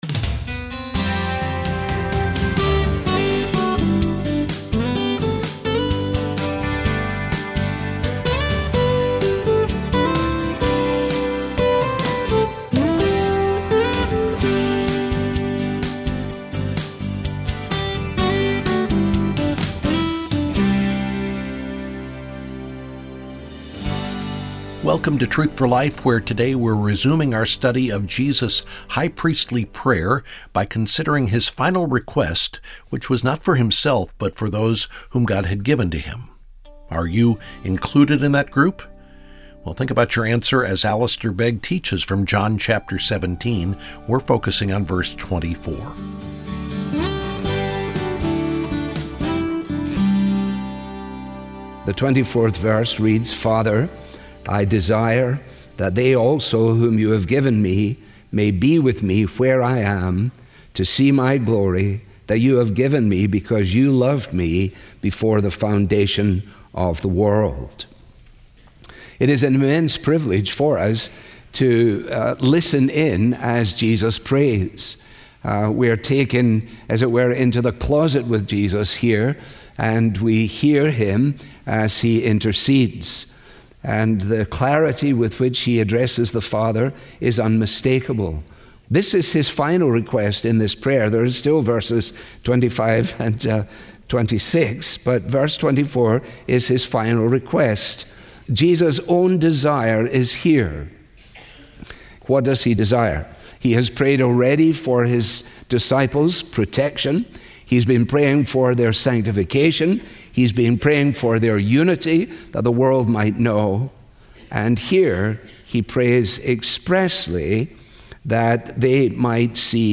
Study along with Truth For Life as Alistair Begg examines Jesus’ final request in the High Priestly Prayer. ----------------------------------------- • Click here and look for "FROM THE SERMON" to stream or read the full message.
Helpful Resources - Learn about God's salvation plan - Read our most recent articles - Subscribe to our daily devotional Follow Us YouTube | Instagram | Facebook | Twitter This listener-funded program features the clear, relevant Bible teaching of Alistair Begg.